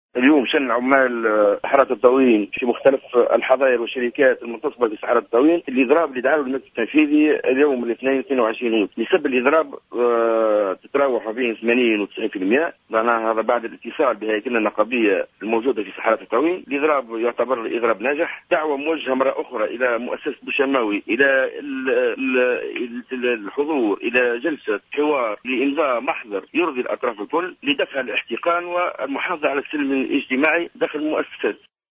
تصريح للجوهرة أف أم